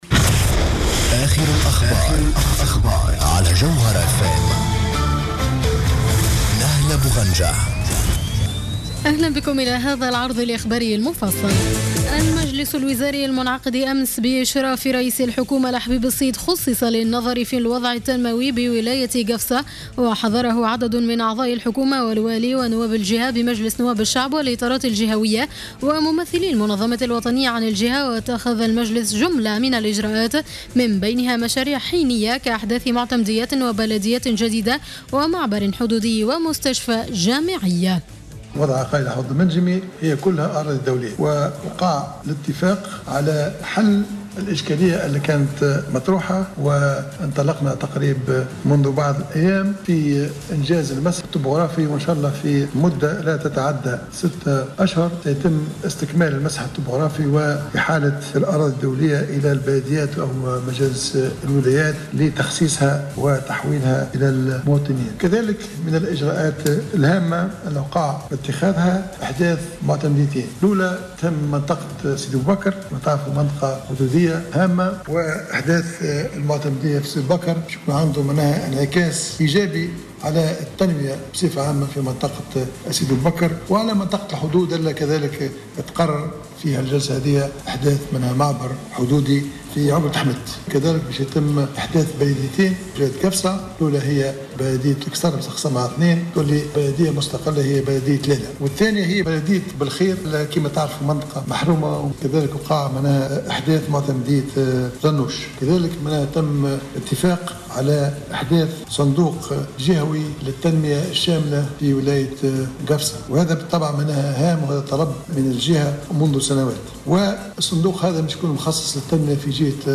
نشرة أخبار منتصف الليل ليوم السبت 16 ماي 2015